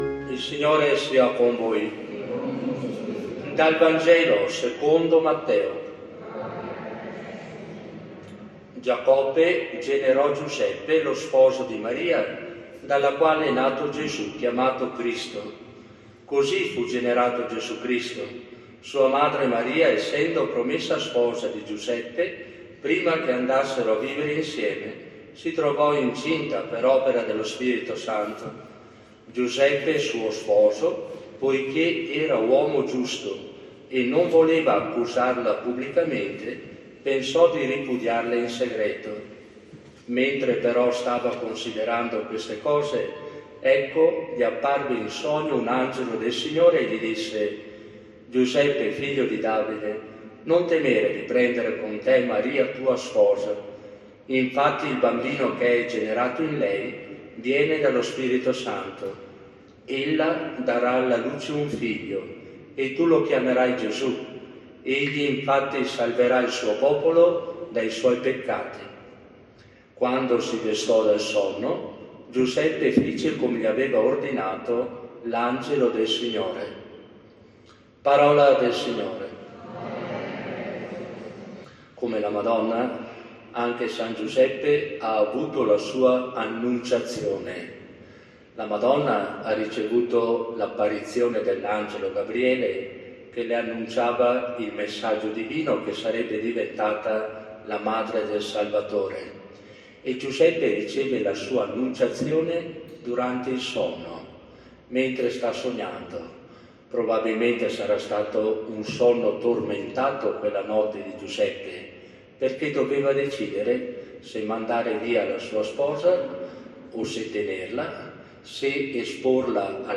SFM-S.-giuseppe-Omelia-2025.mp3